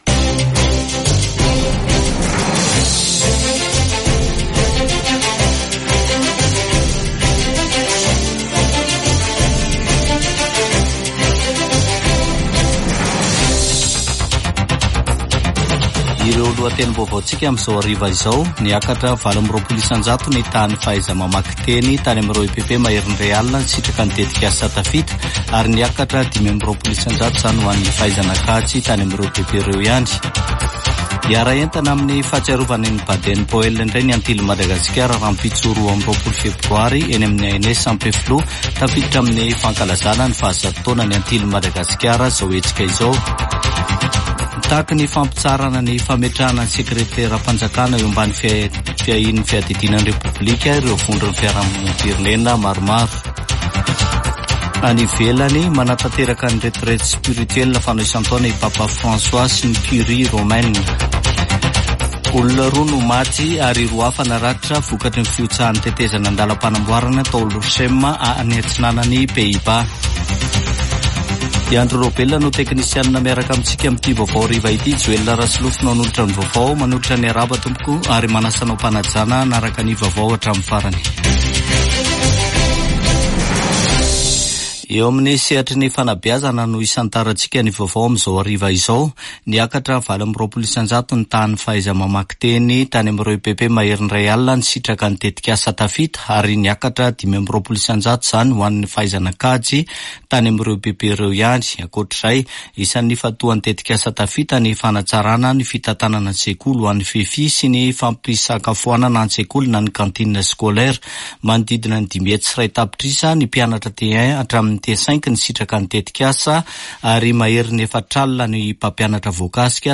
[Vaovao hariva] Alarobia 21 febroary 2024